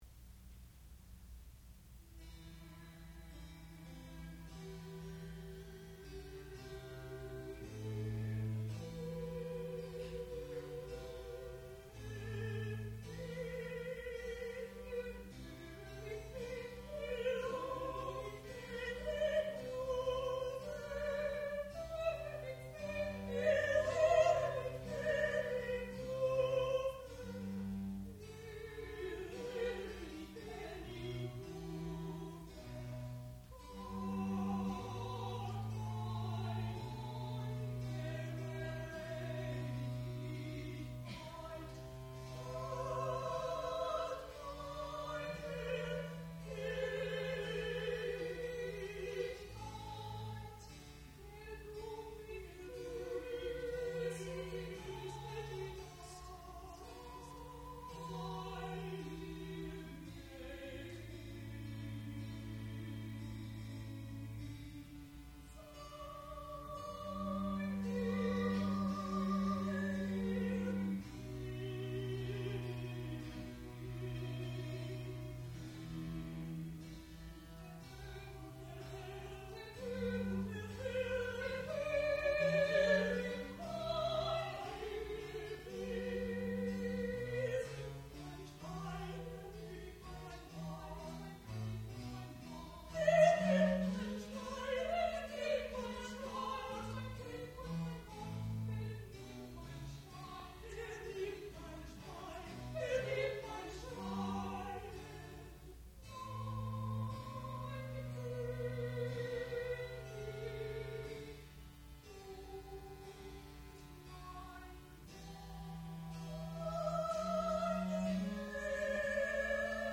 sound recording-musical
classical music
mezzo-soprano
piano
viola da gamba
Graduate Recital
soprano